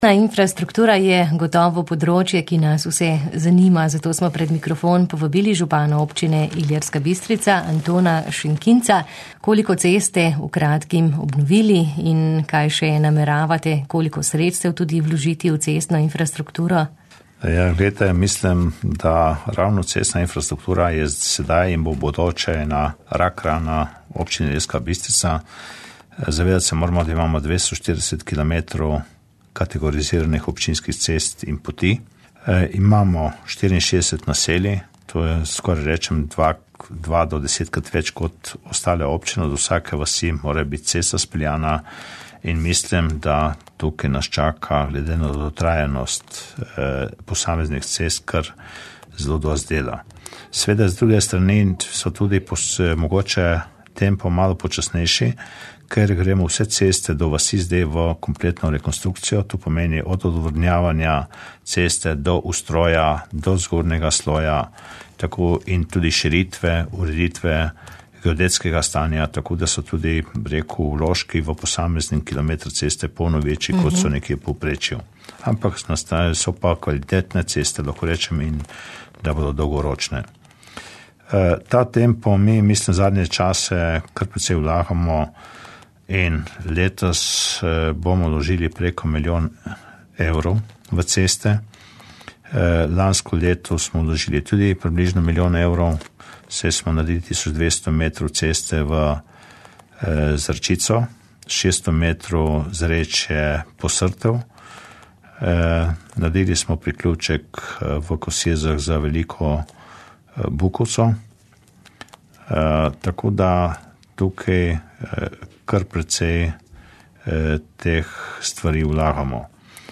Občina Ilirska Bistrica je uspešno pridobila evropska sredstva za obnovo nekaterih cest, nekaj bo seveda morala dodati še sama. O tem, po katerih cestah se bo vozilo udobneje, smo govorili z županom, Antonom Šenkincem.